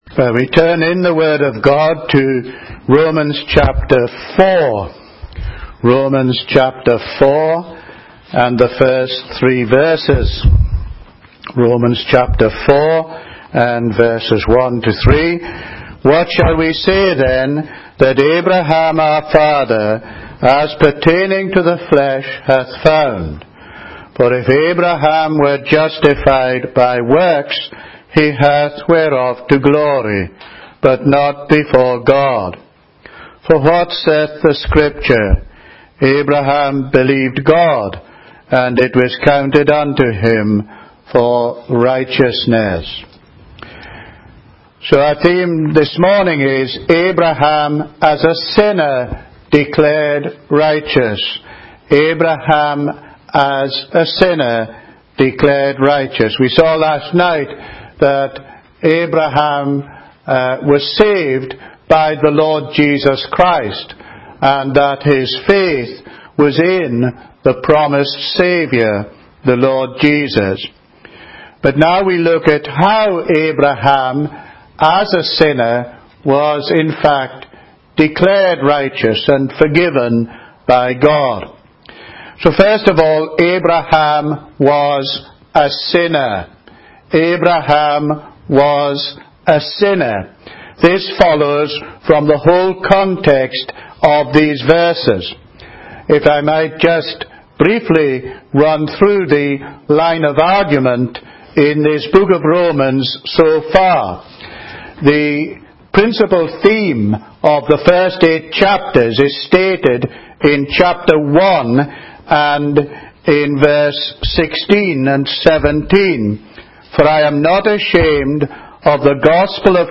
Online Audio Sermon - Abraham as a Sinner Declared Righteous, Romans ch.4 vv.1-3
(Young People's Weekend, Arbroath)